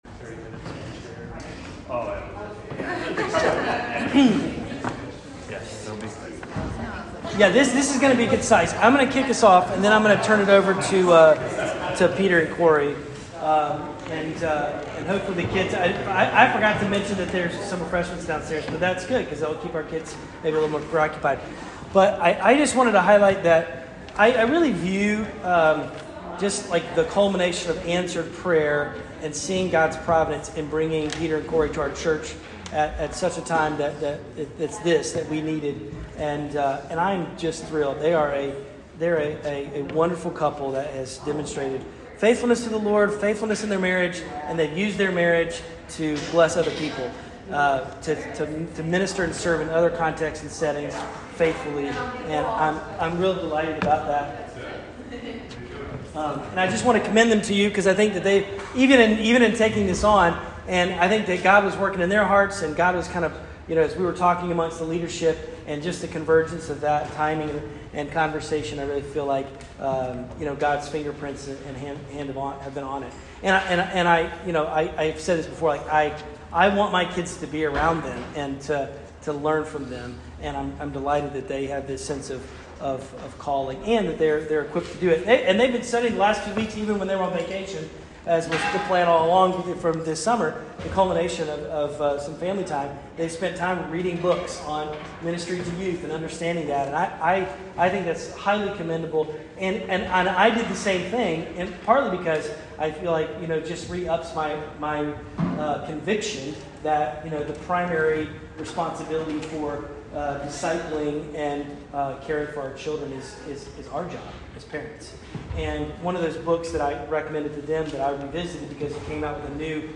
youth-parent-info-meeting-aug-2022.mp3